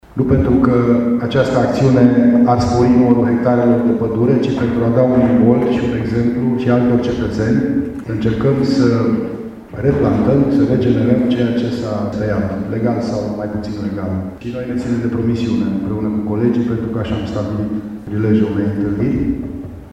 Prefectul de Mureş, Lucian Goga: